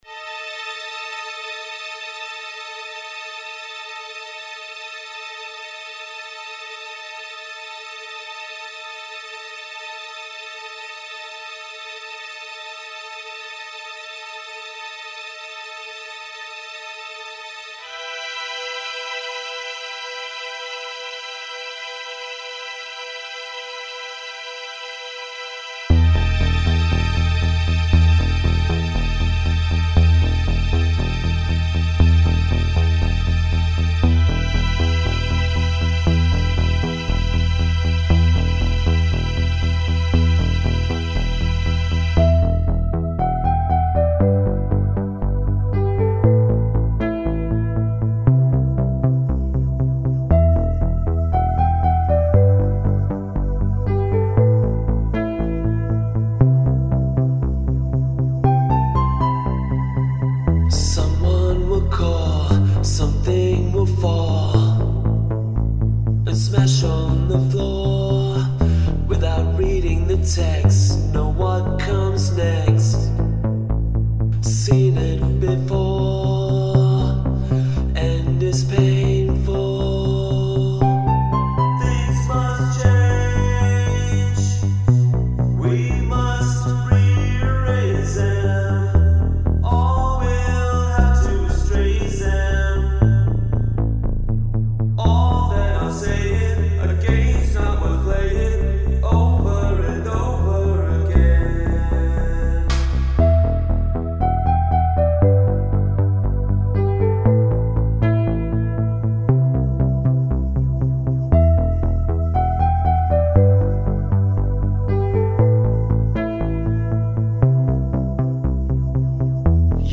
synthpop